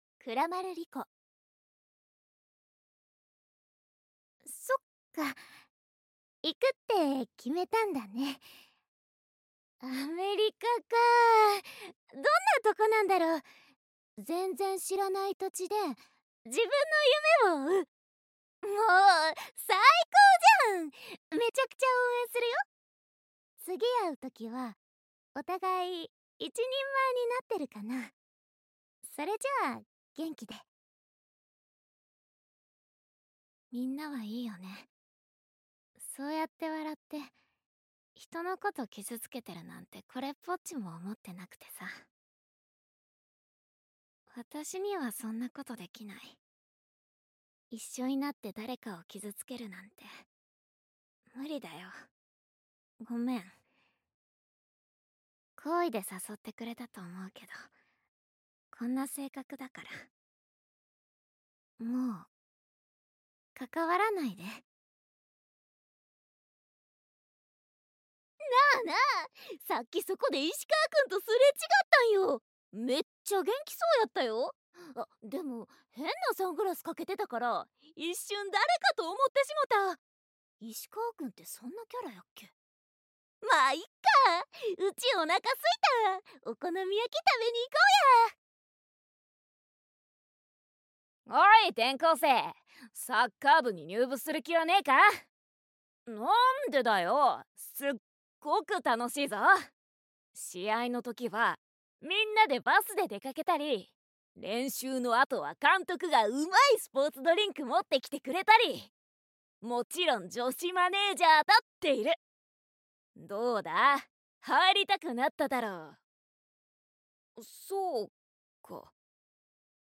サンプルボイス
関西弁